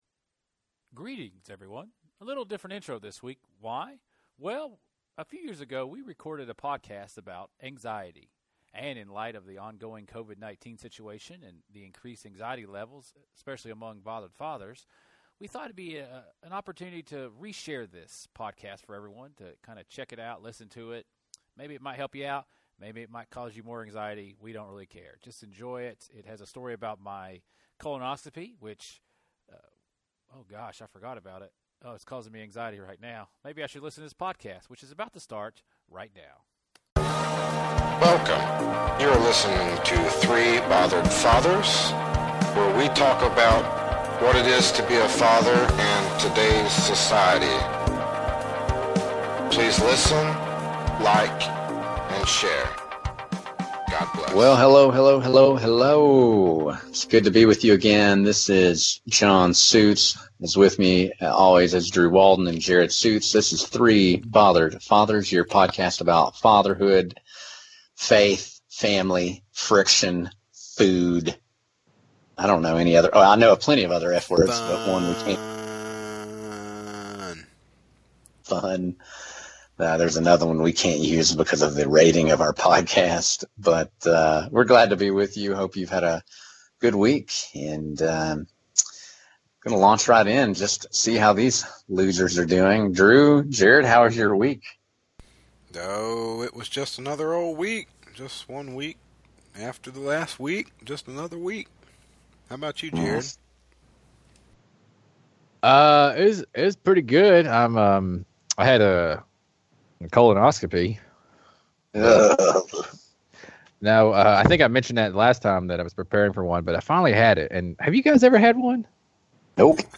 This is an episode we recorded a few years ago about anxiety. Hearing dads talk about how they handle anxiety might help bothered fathers during this season of unknown.